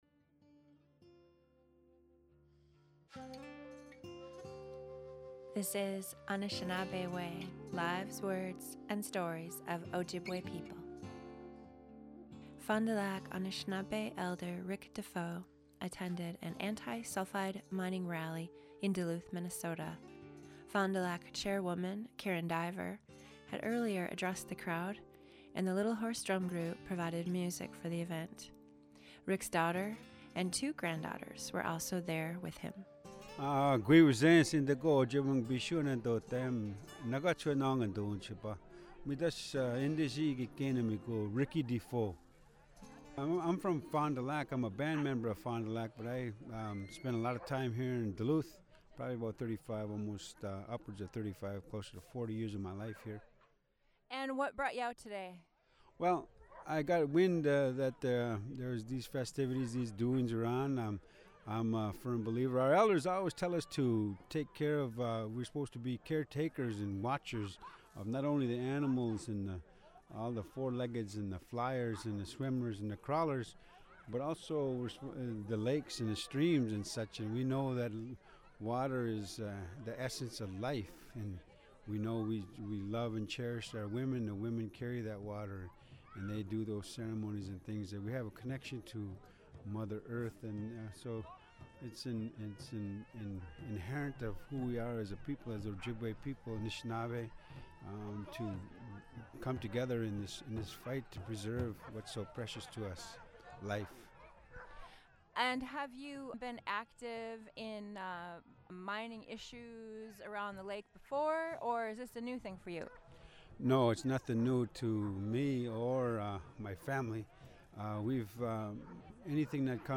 "Anishinaabe Way: Lives, Words and Stories of Ojibwe People" is a radio series that explores the many facets of Ojibwe life.